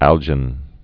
(ăljĭn)